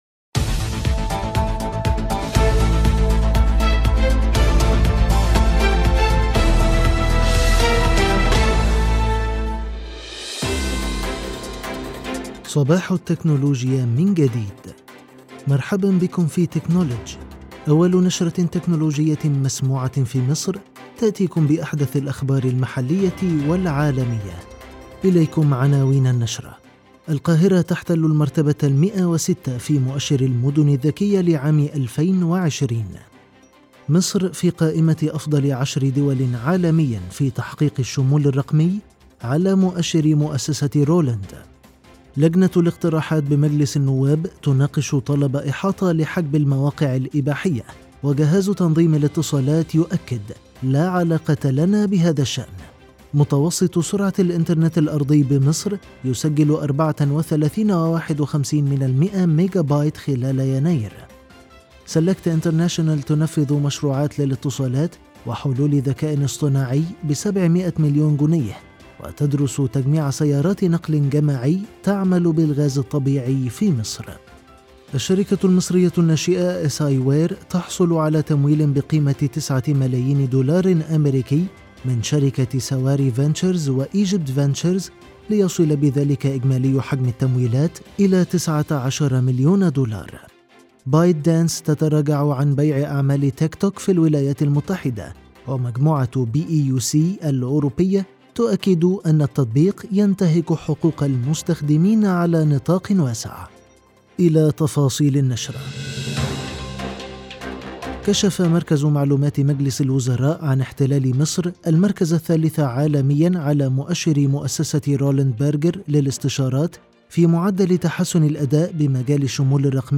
النشرة الأسبوعية
مرحبًا بكم فى  «تكنولدج» أول نشرة تكنولوجية مسموعة فى مصر..تأتيكم بأحدث الأخبار المحلية والعالمية